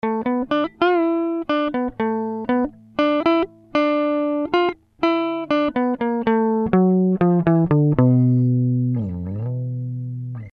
Заготовочка песенки
Да это вообще-то китайская акустика с пьезо.